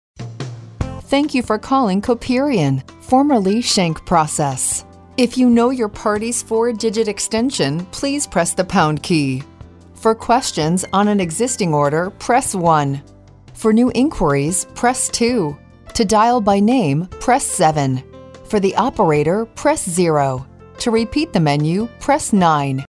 Auto attendant IVR professional music and messages recordings for telephone systems
Auto Attendant and IVR